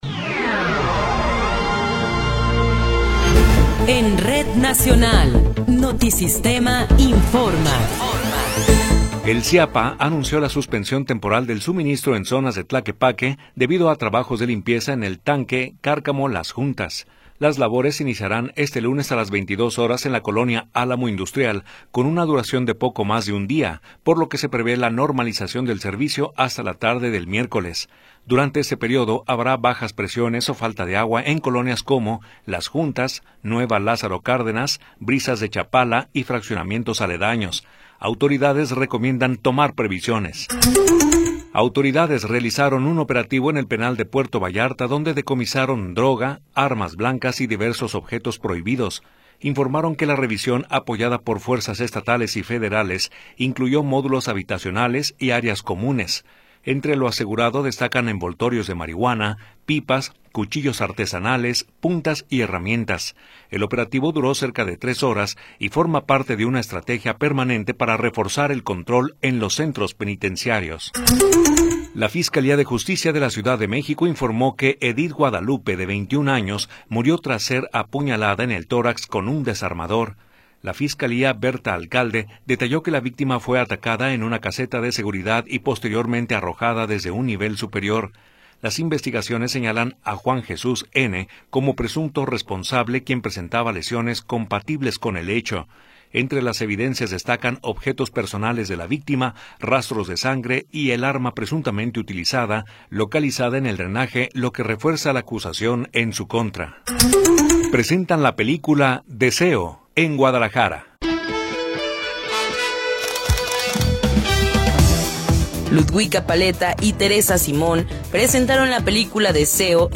Noticiero 19 hrs. – 20 de Abril de 2026
Resumen informativo Notisistema, la mejor y más completa información cada hora en la hora.